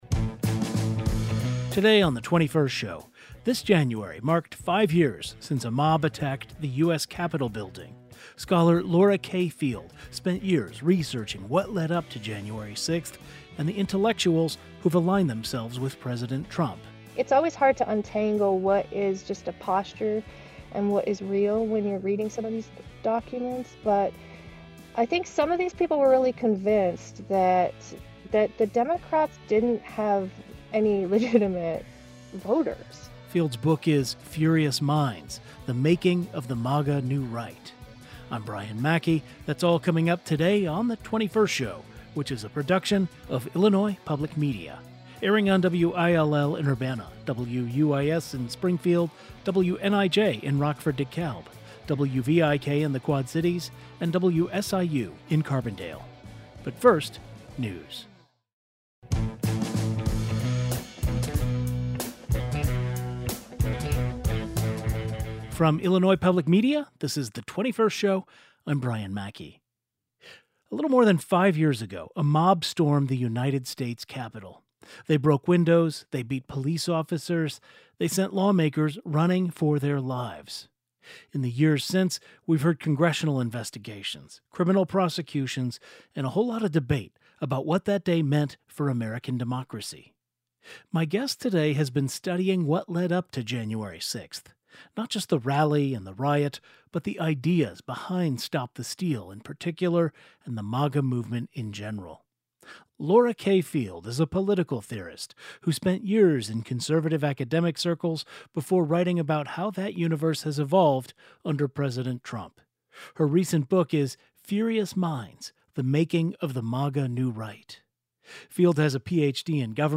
The 21st Show is Illinois' statewide weekday public radio talk show, connecting Illinois and bringing you the news, culture, and stories that matter to the 21st state.
Today's show included a rebroadcast of the following "best of" segment first aired January 6, 2026: Five years after January 6 Capitol riot, author explores creation of MAGA.